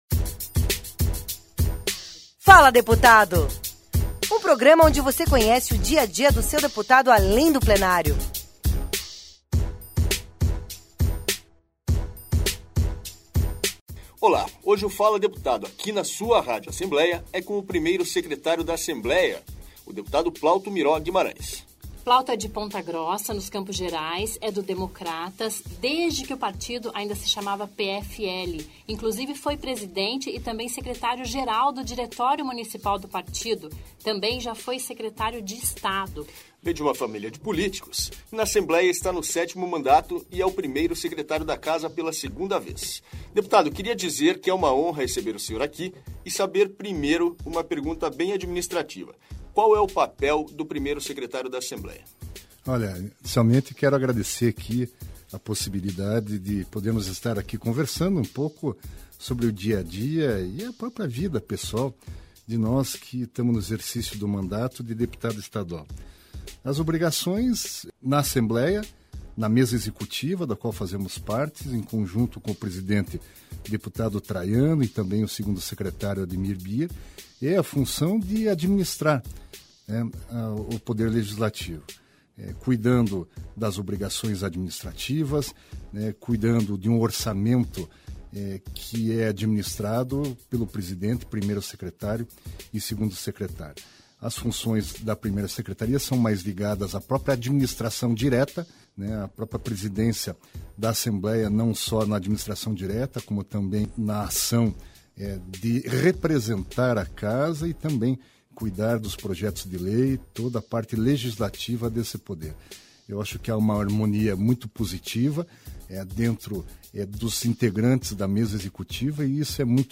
Conheça mais de Plauto Miró, o entrevistado do "Fala Deputado" desta semana